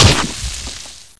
GelHit.ogg